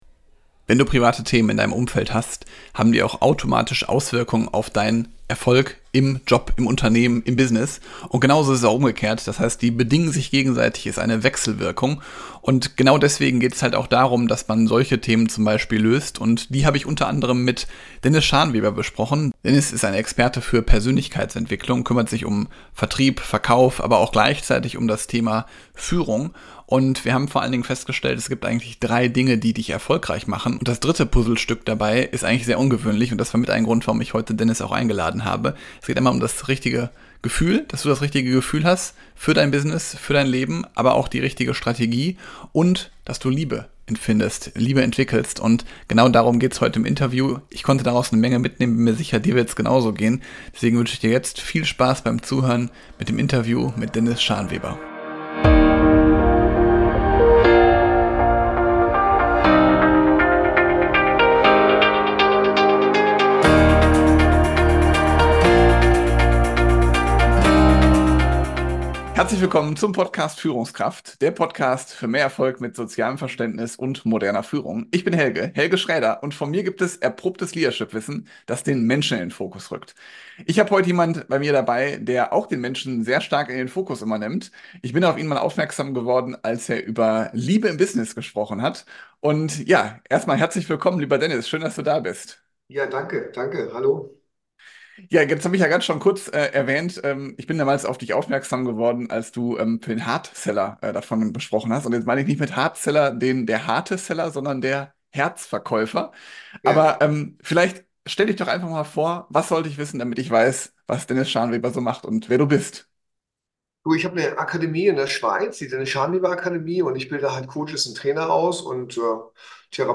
Nr. 206 - Gefühl, Strategie und Liebe als Elemente für Erfolg - Interview